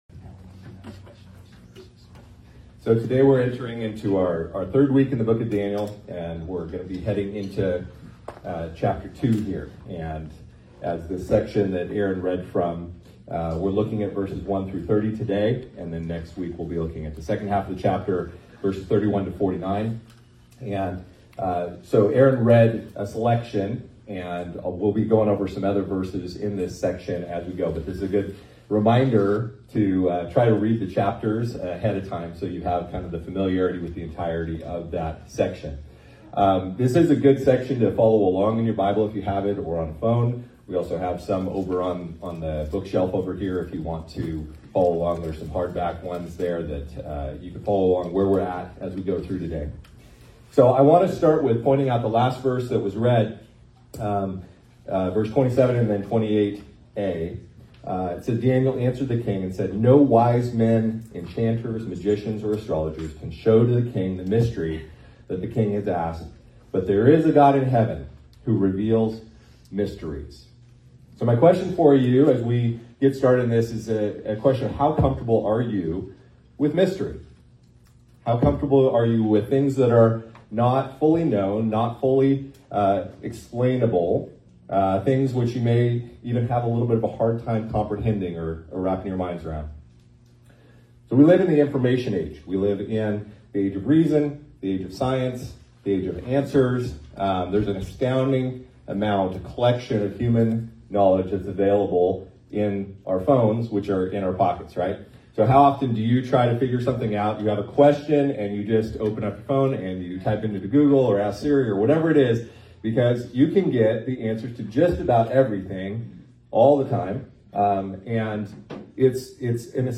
Sermons - ABIDE Chico